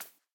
minecraft / sounds / mob / rabbit / hop1.ogg
hop1.ogg